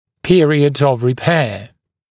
[‘pɪərɪəd əv rɪ’peə][‘пиэриэд ов ри’пэа]период восстановления (напр. после хирургической операции)